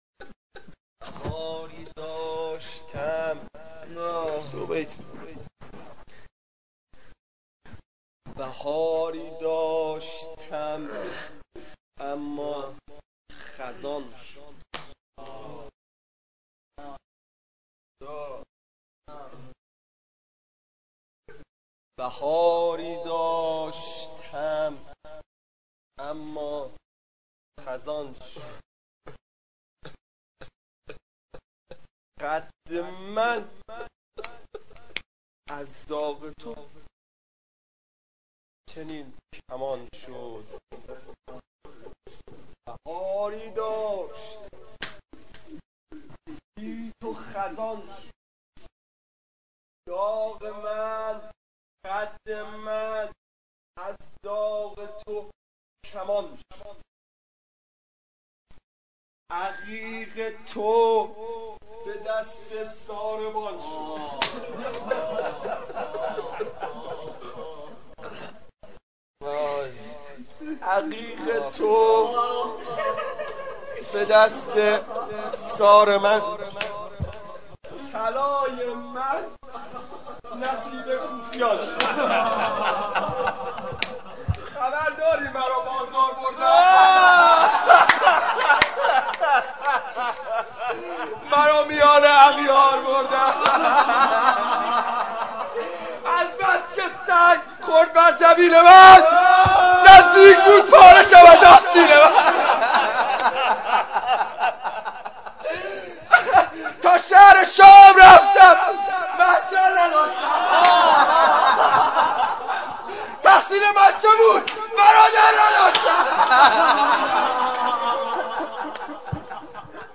مراسم هفتگی؛ 14 ذی القعده 1432؛ روضه حضرت سیدالشهداء و علیه السلام؛ قسمت دوم